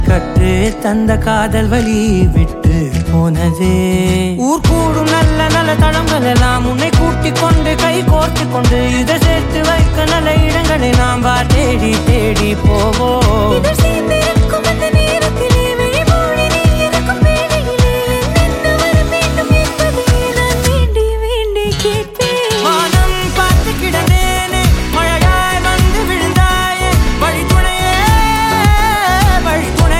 spiritual and emotional song